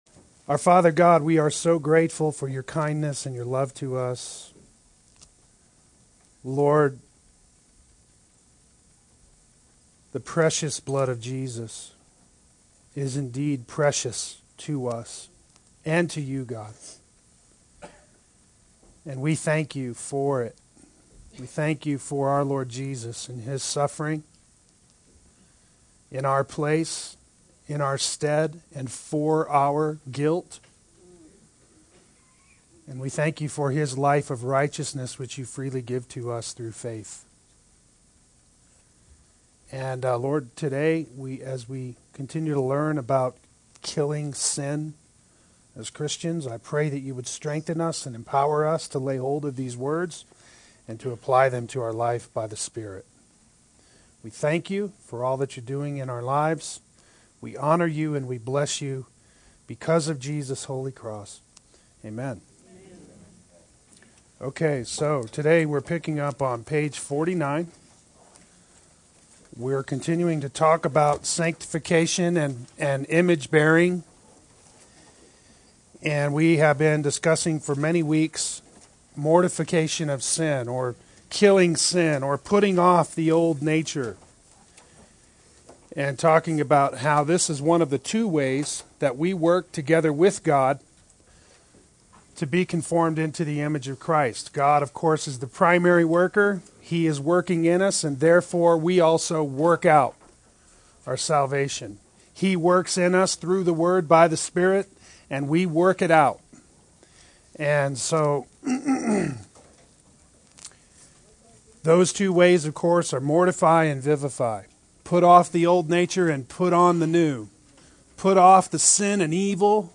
Play Sermon Get HCF Teaching Automatically.
Part 5 Adult Sunday School